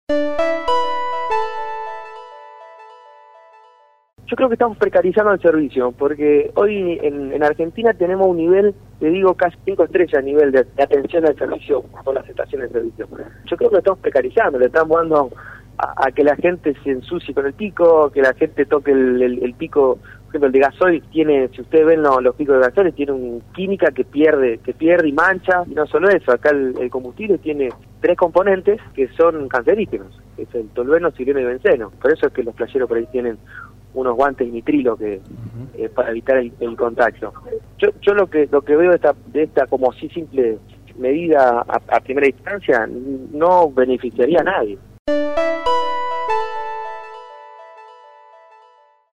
Consultado al respecto y en diálogo con el programa La barra de Casal